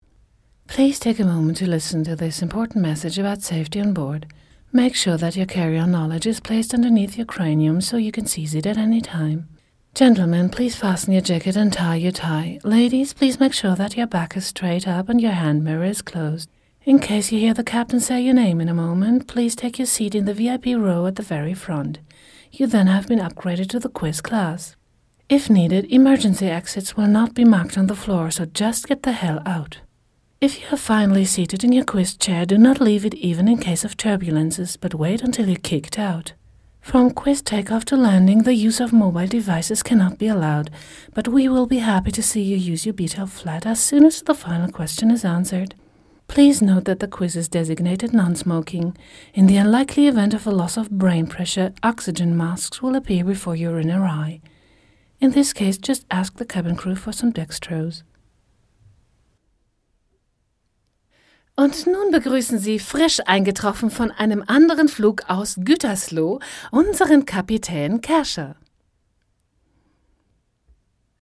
Hier findest du eine Auswahl verschiedener Stimmfarben und Sprachen, die ich bedienen kann: